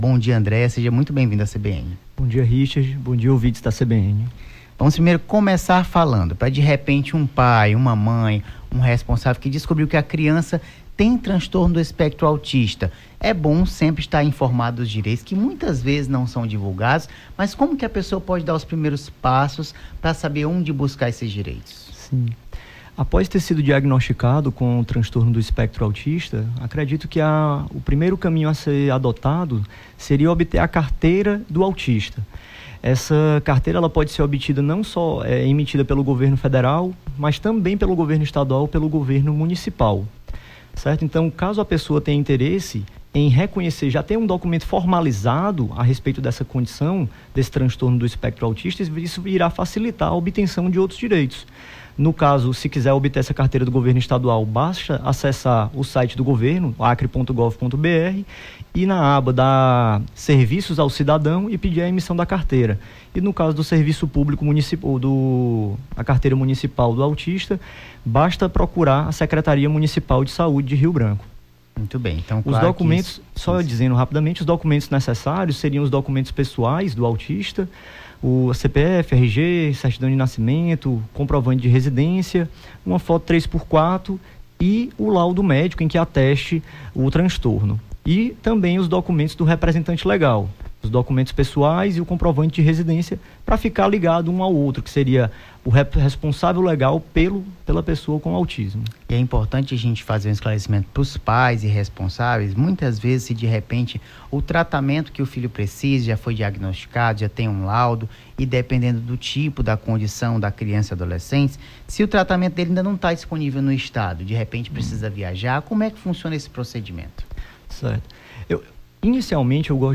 Nome do Artista - CENSURA - ENTREVISTA (SEUS DIREITOS - CARTILHA AUTISMO) 17-07-23.mp3